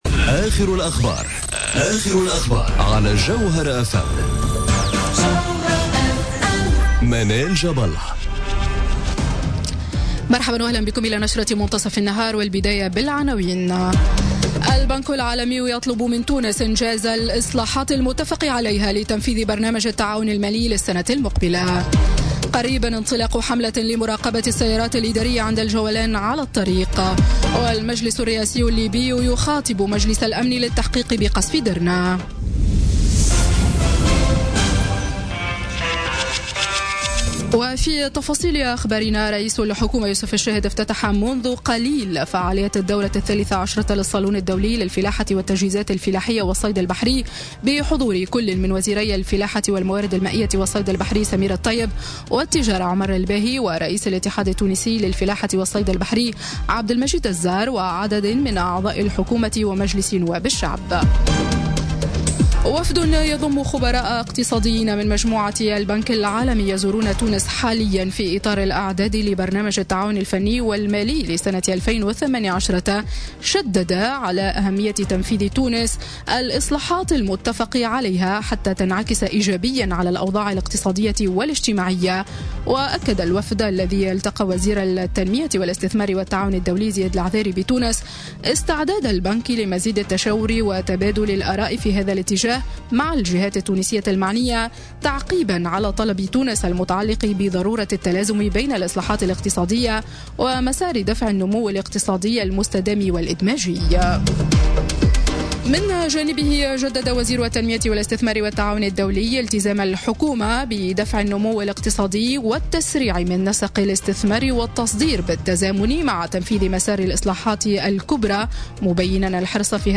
نشرة أخبار منتصف النهار ليوم الثلاثاء 31 أكتوبر 2017